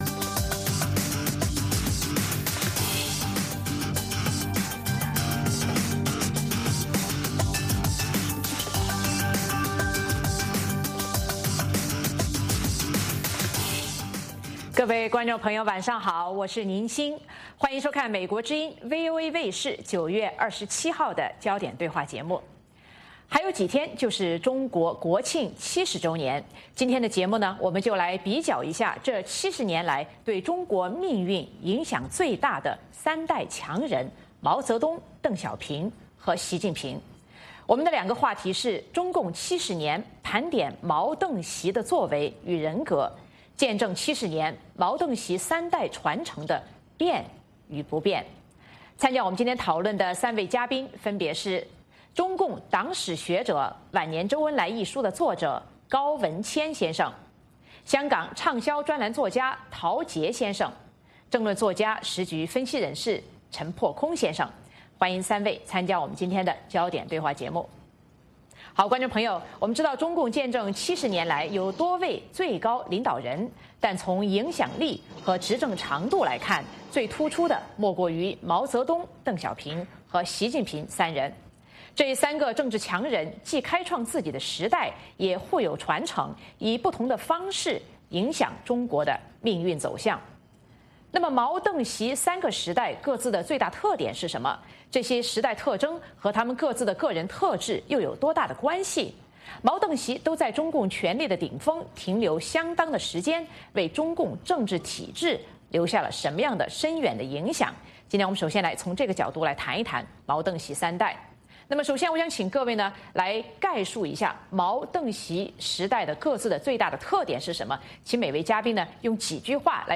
美国之音中文广播于北京时间每周五晚上9-10点播出《焦点对话》节目。《焦点对话》节目追踪国际大事、聚焦时事热点。邀请多位嘉宾对新闻事件进行分析、解读和评论。